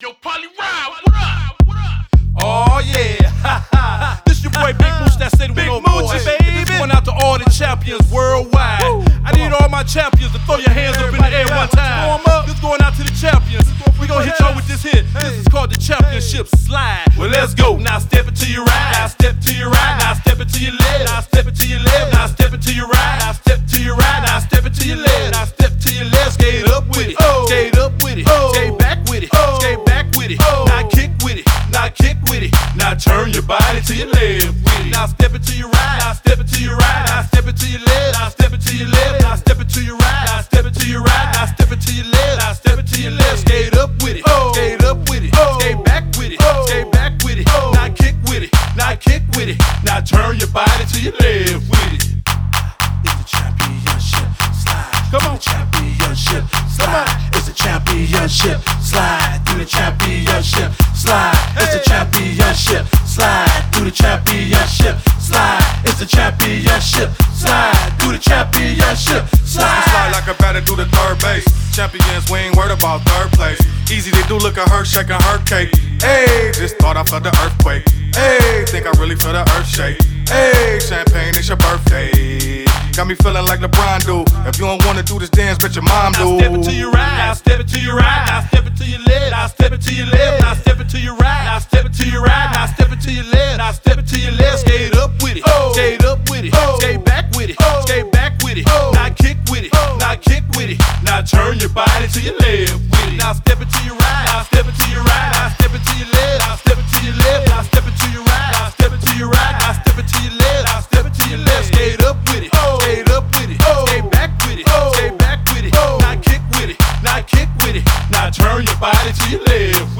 anthem line dance